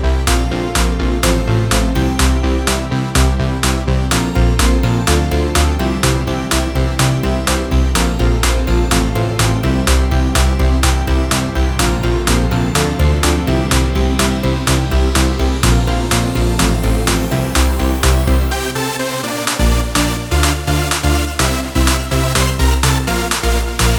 no Backing Vocals R'n'B / Hip Hop 4:11 Buy £1.50